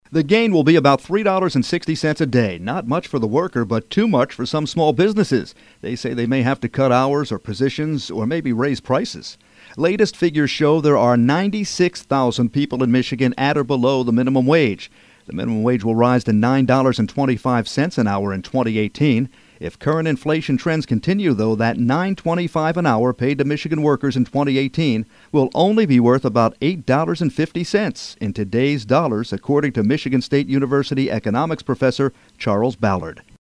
The minimum wage has jumped to $8.90 in Michigan. Business reporter